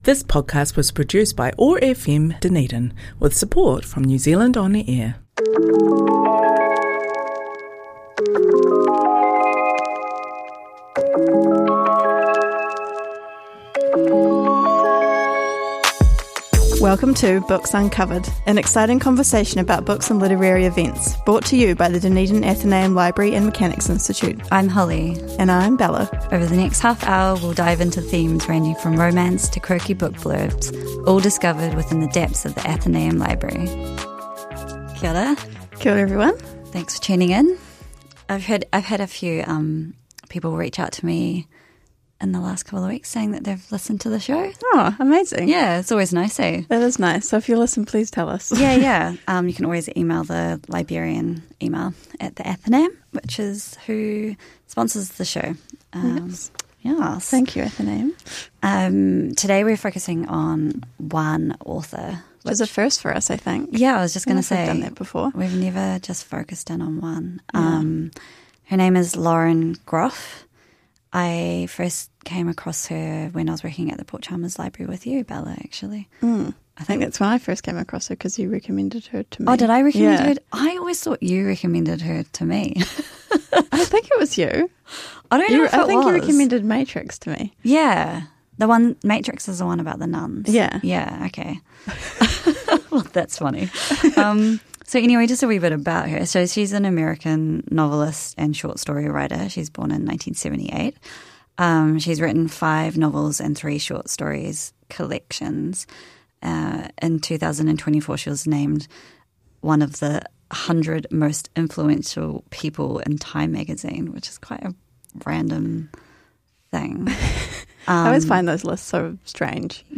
We chat about a couple of books from American writer Lauren Groff. Her 2022 novel Matrix and her 2026 short story collection Brawler. Broadcast on OAR 105.4FM Dunedin